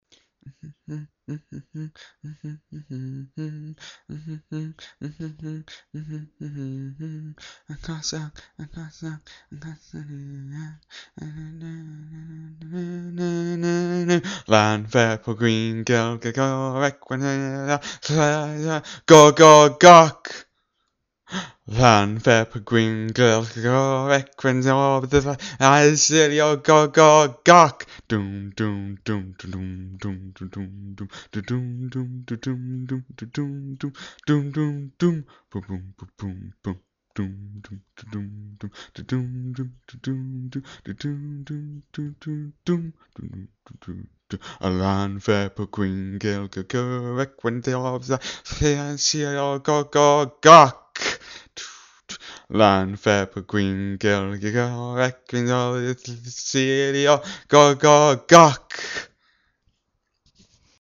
This is me attempting to pronounce such a place name. Also, I only even know how to be close by singing it a lá the Llanfair PG Rap.